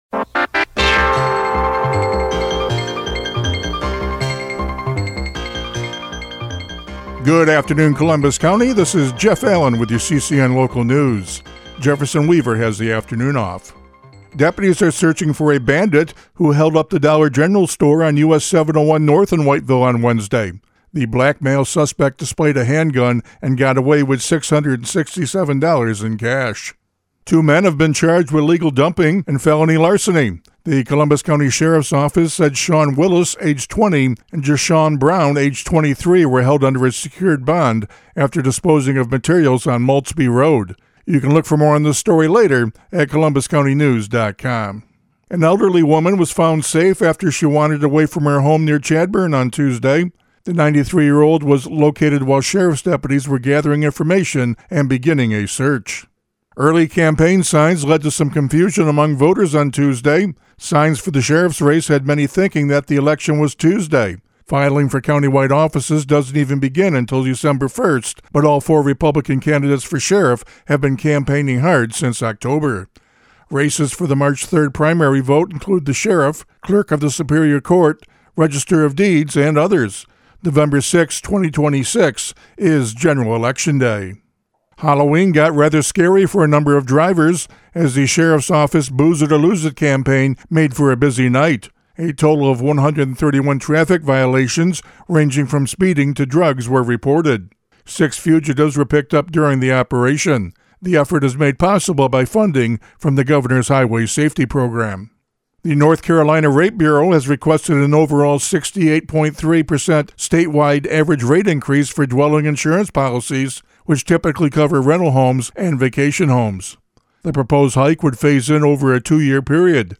CCN Radio News — Afternoon Report for November 6, 2025
CCN-AFTERNOON-NEWS-REPORT.mp3